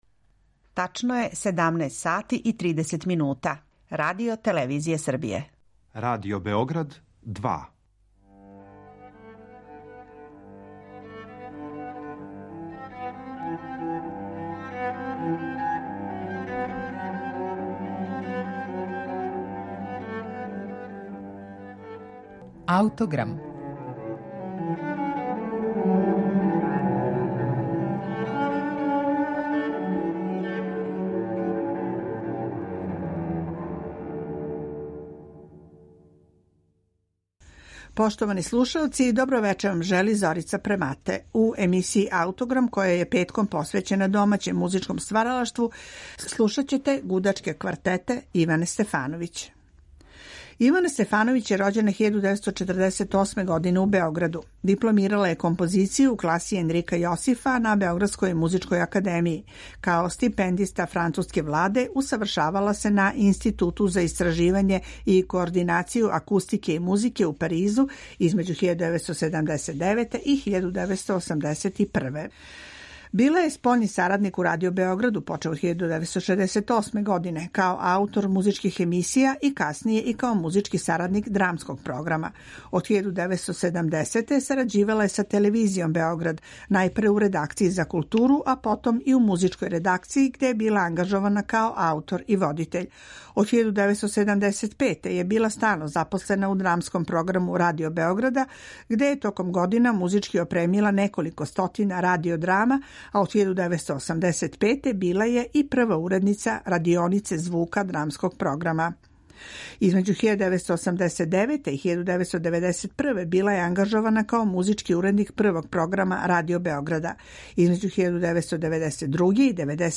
гудачка квартета